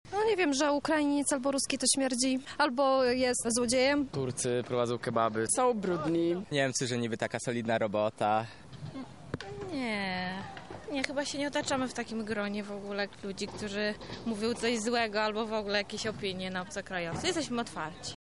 Zapytaliśmy przechodniów o tym, jakie opinie słyszeli o obcokrajowcach.